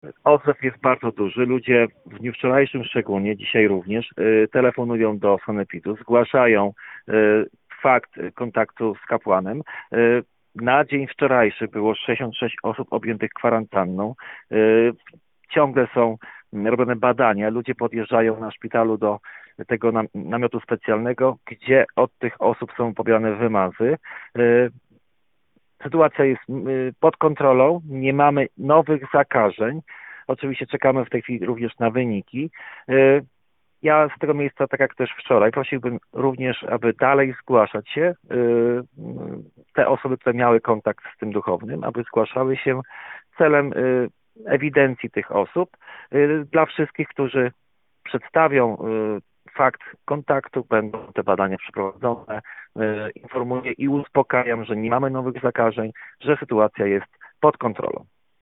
– Sytuacja jest pod kontrolą – mówi starosta Marcin Piwnik: